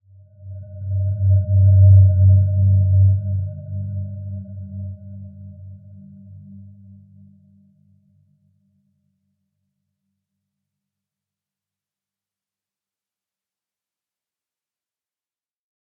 Dreamy-Fifths-G2-p.wav